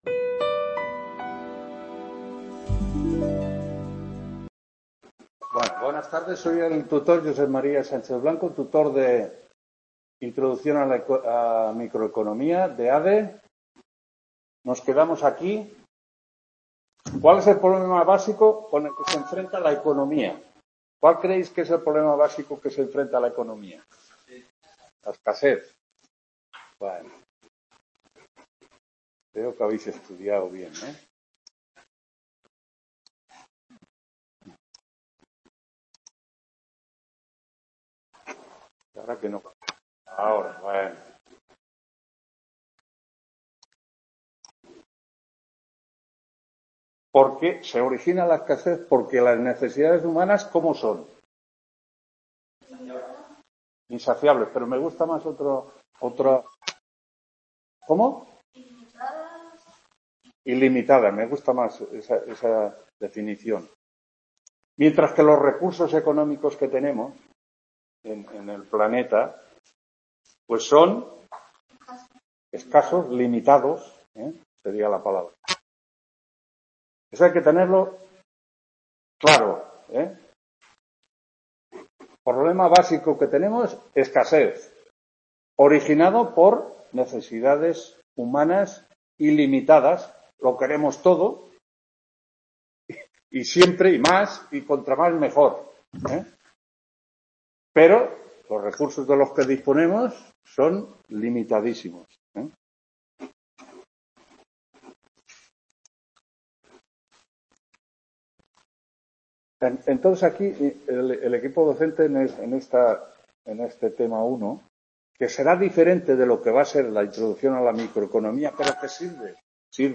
2ª TUTORÍA INTRODUCCIÓN A LA MICROECONOMÍA (Nº 230)… | Repositorio Digital